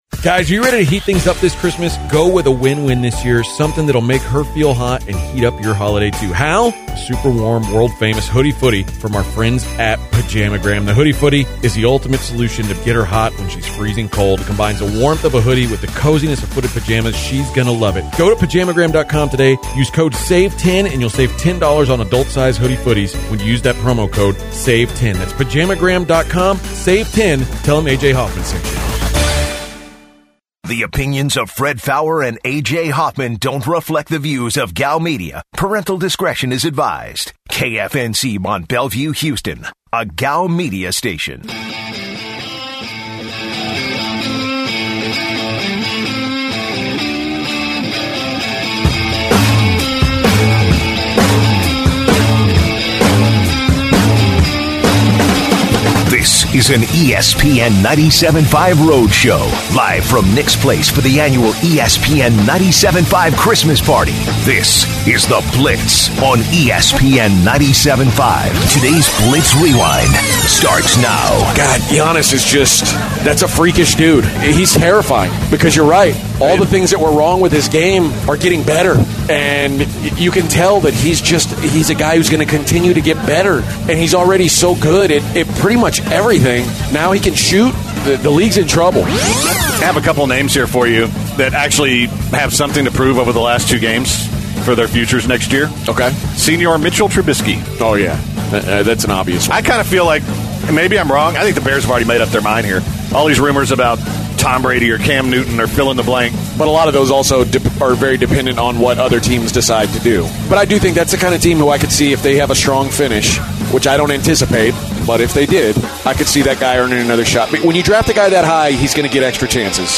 broadcast live from Nick's Place for our ESPN 97.5 Christmas Party!